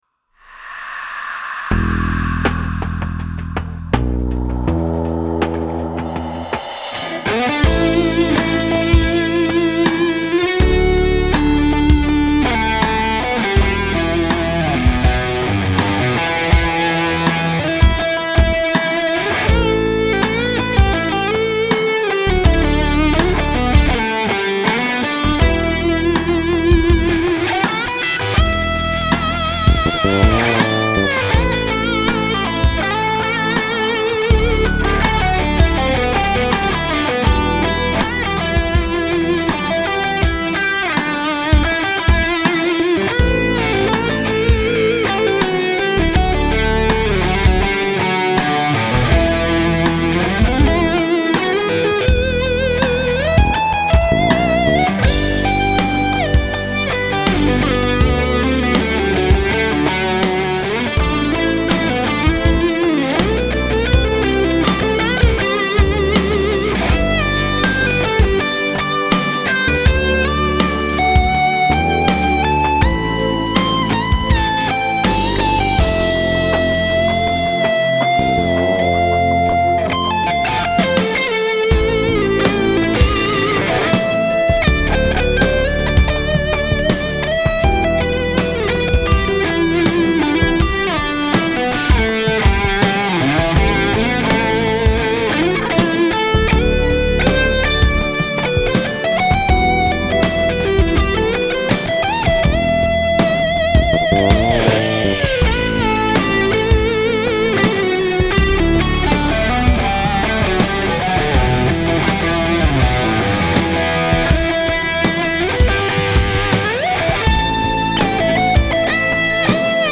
Ach.. ist ein First & One Take... also keine Gefangenen :-)